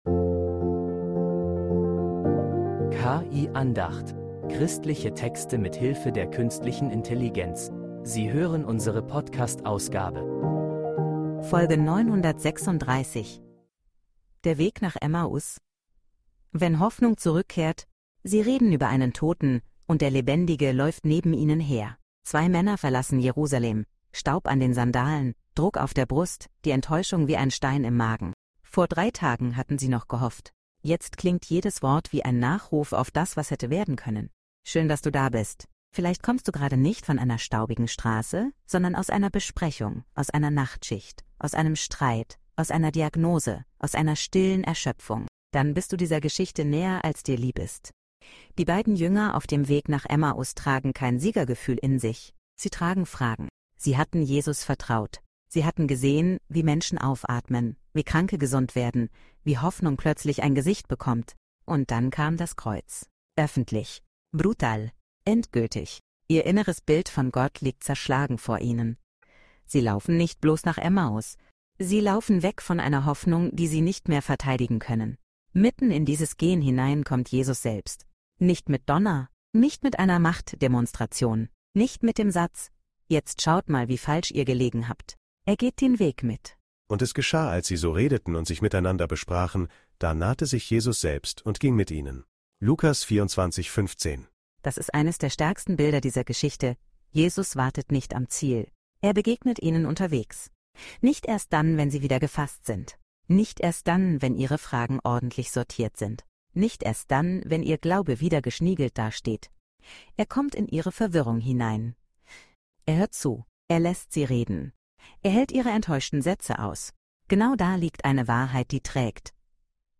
Diese Andacht erzählt von einem Weg, der nicht in der Resignation